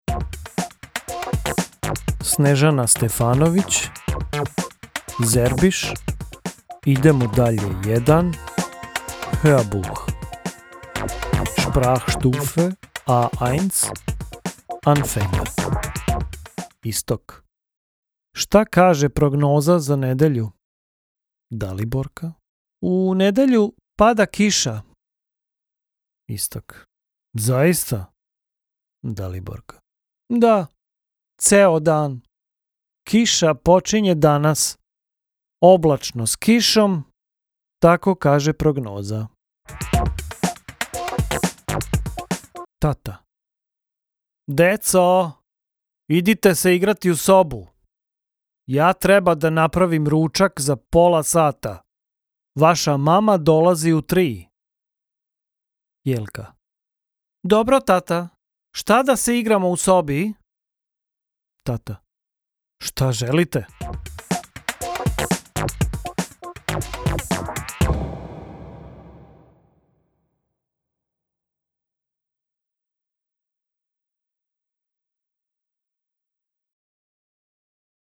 Hörbuch
Hoerprobe-Serbisch-Idemo-dalje-1-Hoerbuch.mp3